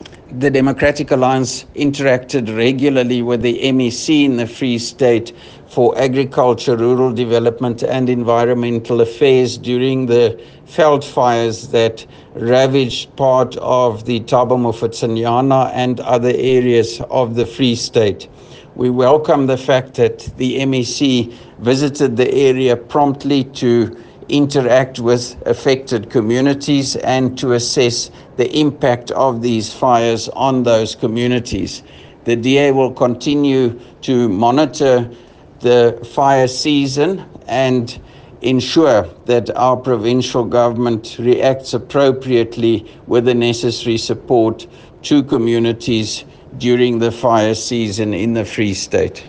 Afrikaans soundbites by Roy Jankielsohn MPL and images here,here,here and here